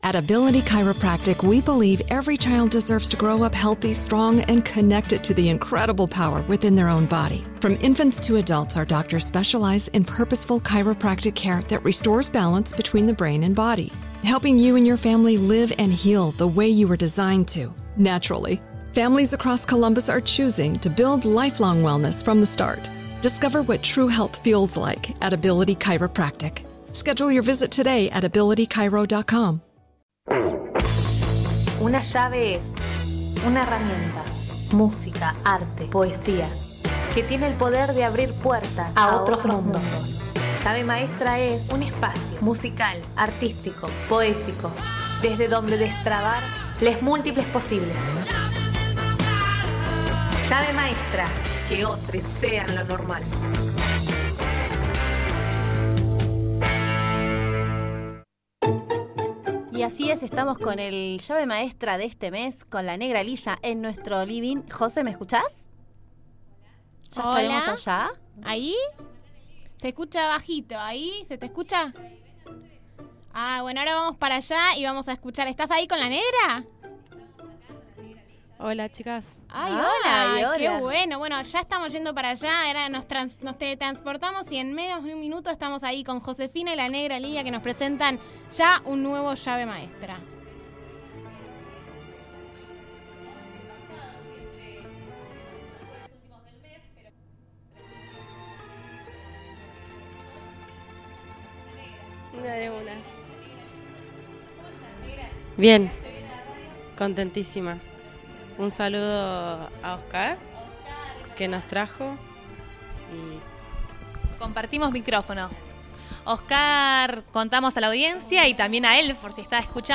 cantaron algunos temas en vivo